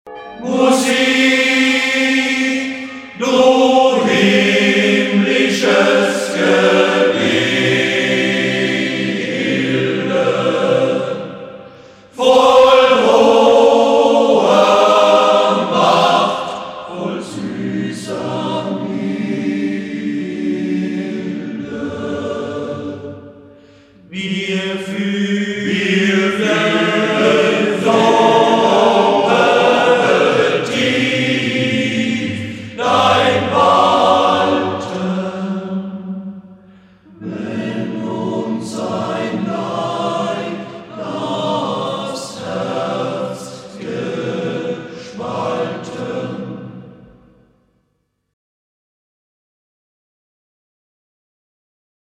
A-capella-Chorgesang
2003 / festliche und geistliche Lieder